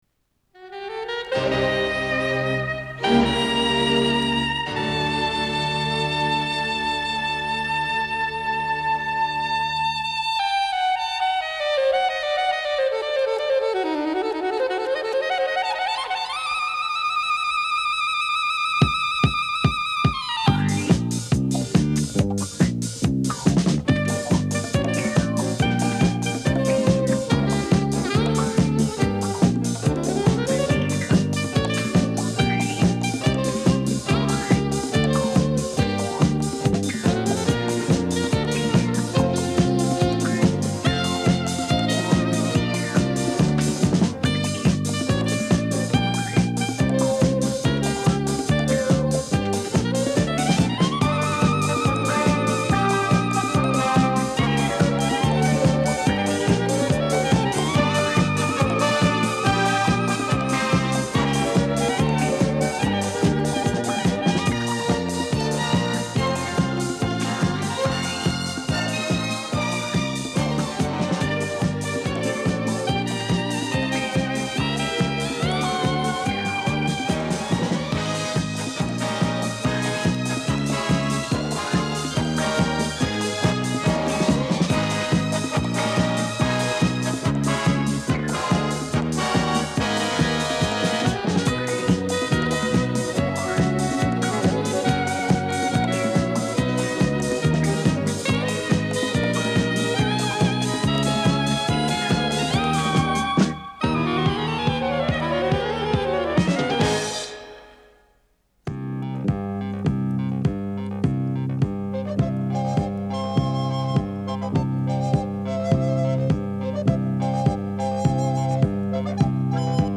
Играют оркестры Чехословакии.